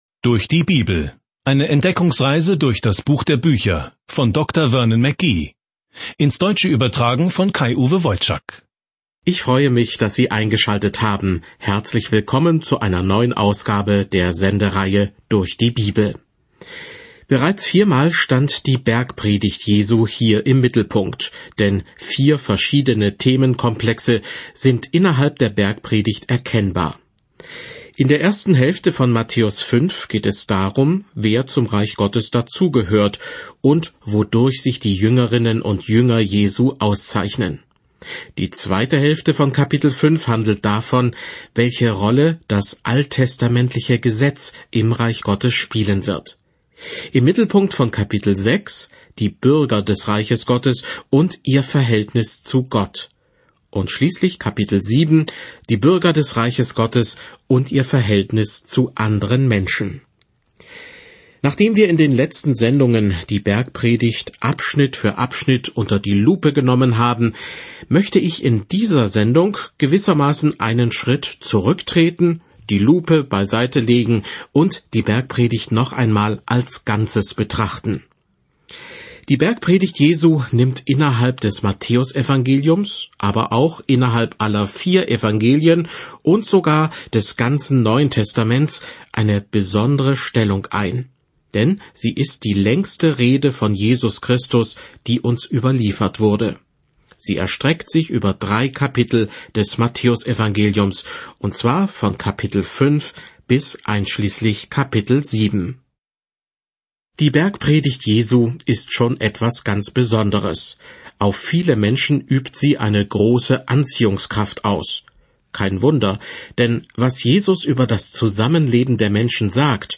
Die Heilige Schrift Matthäus 5 Matthäus 6 Matthäus 7 Tag 11 Diesen Leseplan beginnen Tag 13 Über diesen Leseplan Matthäus beweist jüdischen Lesern die gute Nachricht, dass Jesus ihr Messias ist, indem er zeigt, wie sein Leben und sein Dienst die Prophezeiung des Alten Testaments erfüllten. Reisen Sie täglich durch Matthäus, während Sie sich die Audiostudie anhören und ausgewählte Verse aus Gottes Wort lesen.